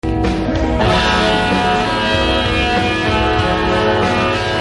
Really short, and hard to hear